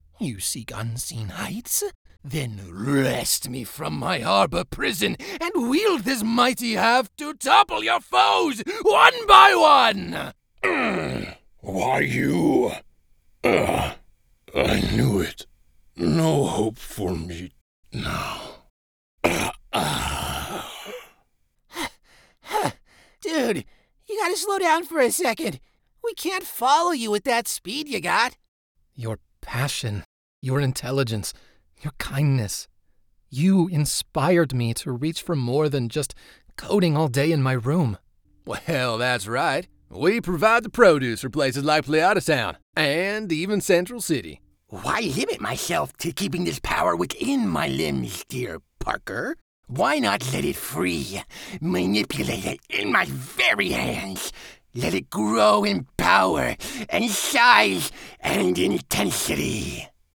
2024 Character Sampler